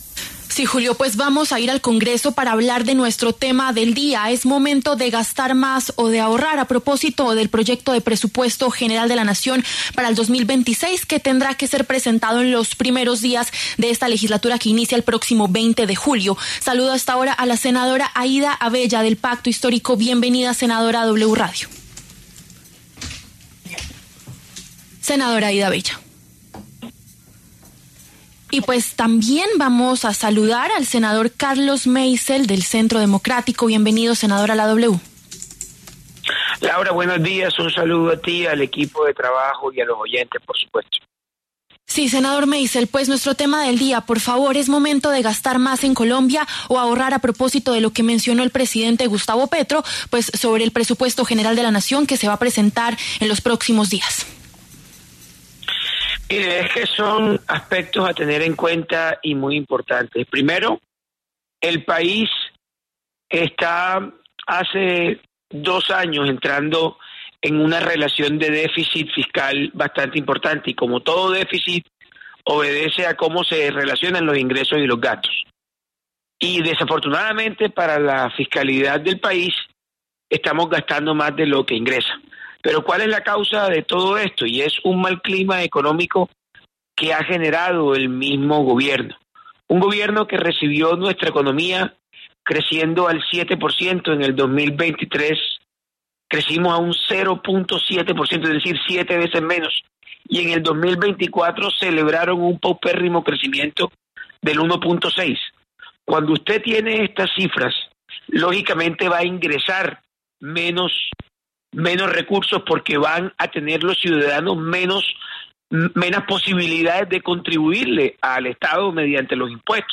Los senadores Carlos Meisel, del Centro Democrático, y Aída Avella, del Pacto Histórico, pasaron por los micrófonos de La W.